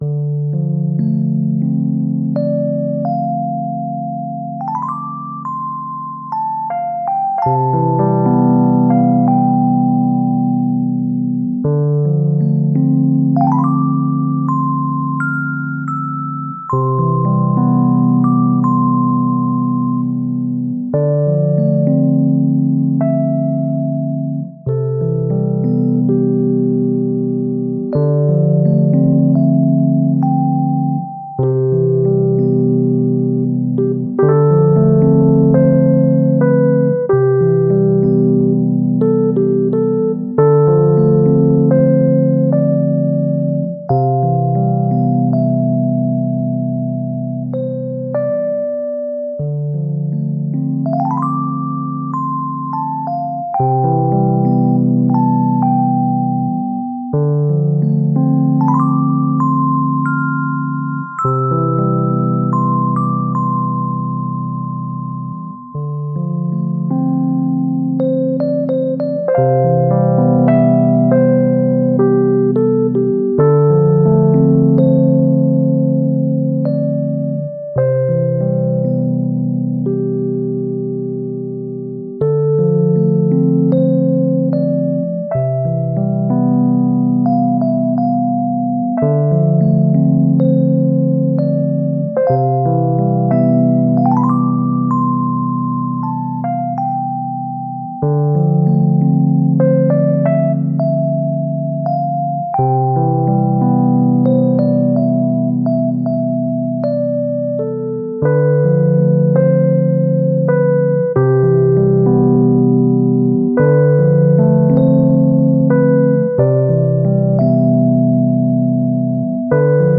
Slow jazzy E.Piano. / Date: 07.04.2024